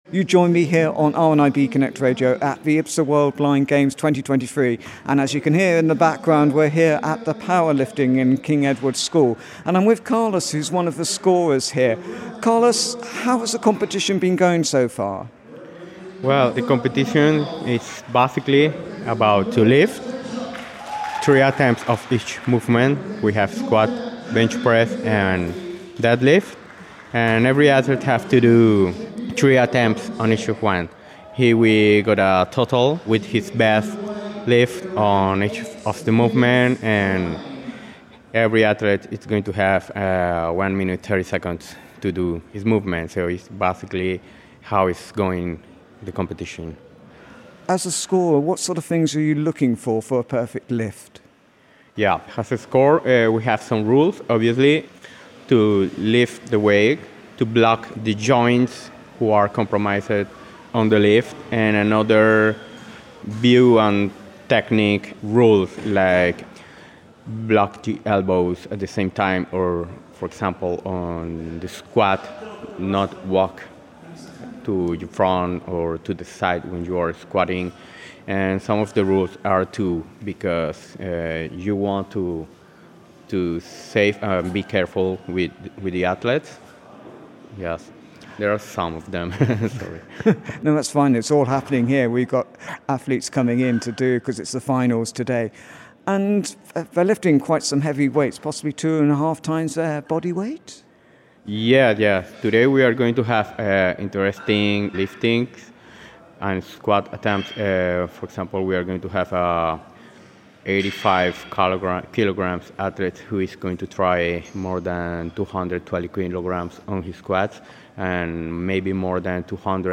As the competition hots up on the final day of the power lifting at the IBSA World Blind Games 2023